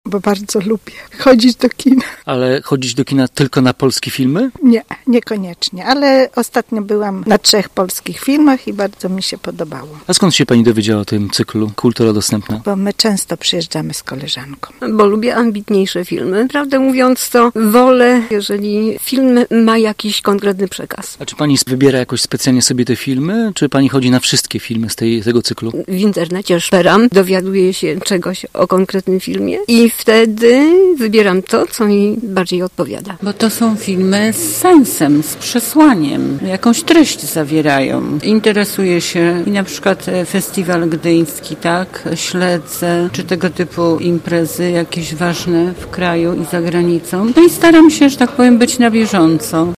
Seanse cieszą się dużym zainteresowaniem i są pozytywnie oceniane przez sympatyków kina. Mówią gorzowianki